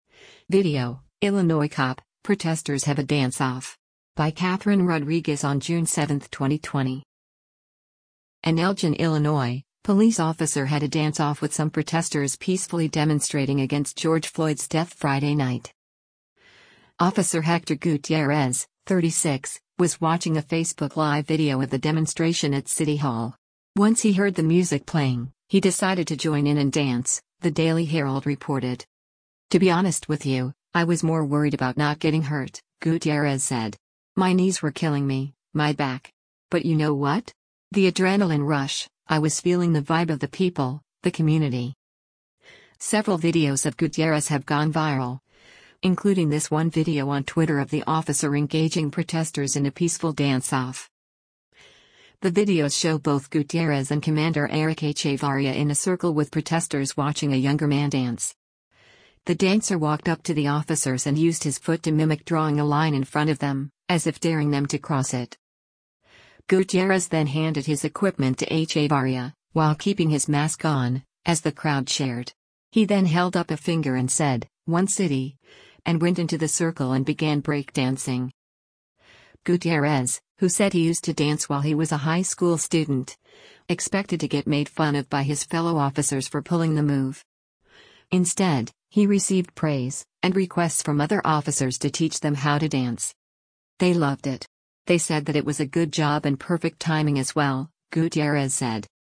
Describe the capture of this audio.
VIDEO: Illinois Cop, Protesters Have a Dance Off at City Hall